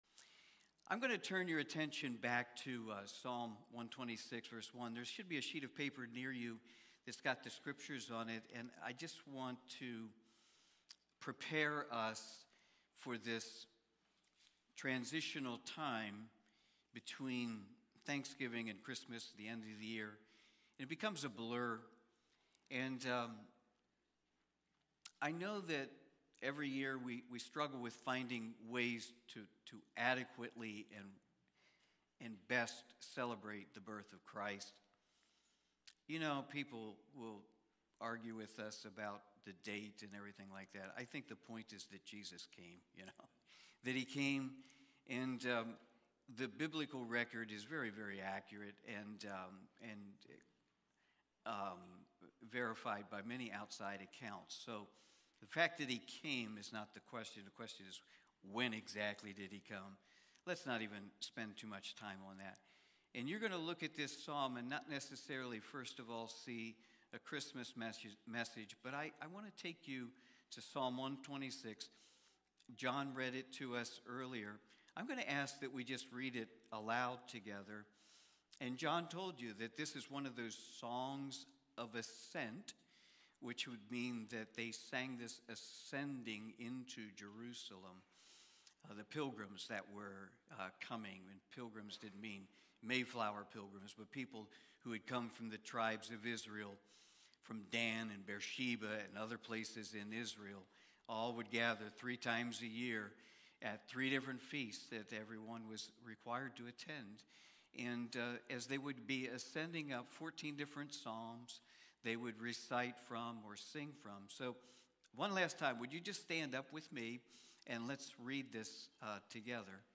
Revelation Watch Listen Save Cornerstone Fellowship Sunday morning service, livestreamed from Wormleysburg, PA.